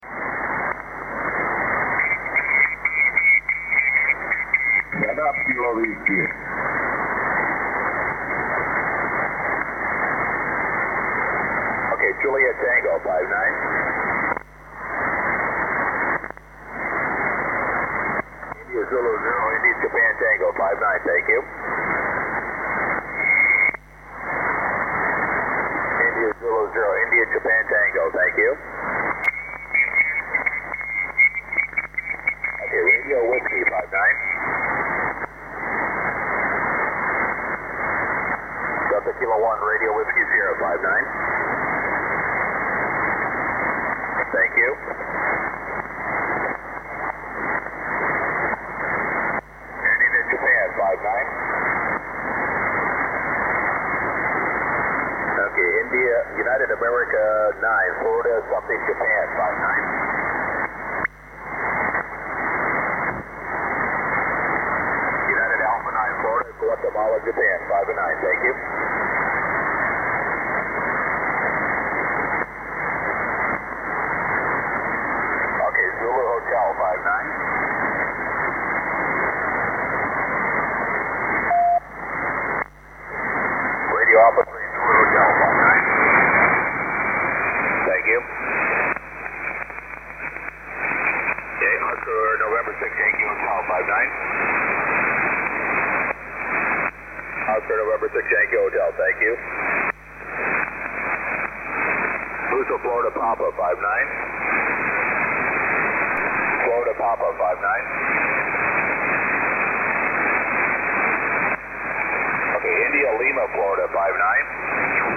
FT5ZM 15m SSB 27-01-14